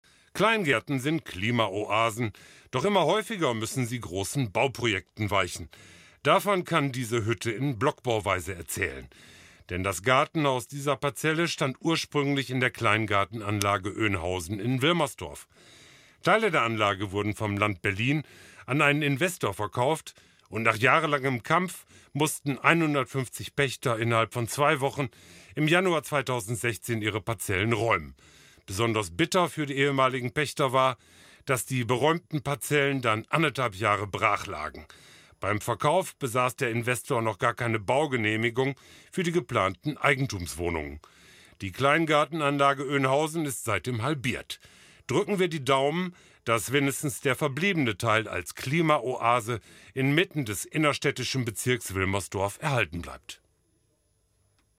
Die bisherigen Texte wurden von einem professionellen Sprecheraufgenommen, der Vereinsmitglied ist.